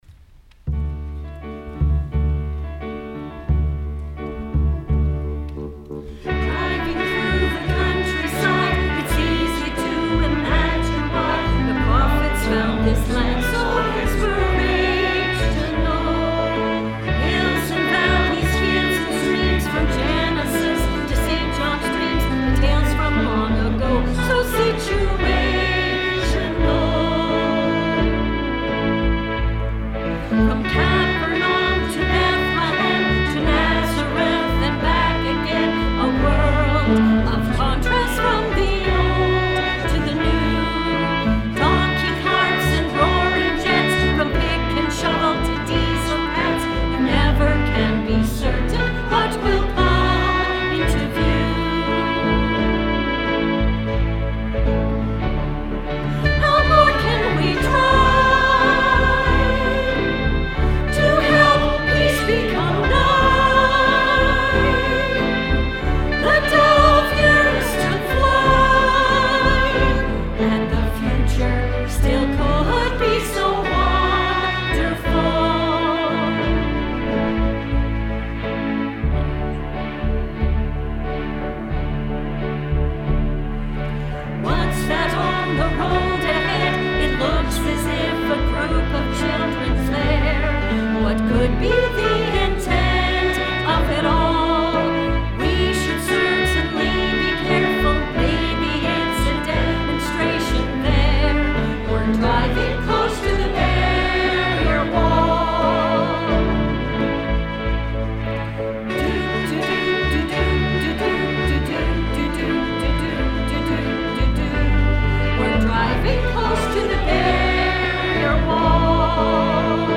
Our choir was seated in their usual pews at the front left of the Sanctuary, and in front of them, to the side, there was the front end of a bus, made in a rather large form, from cardboard.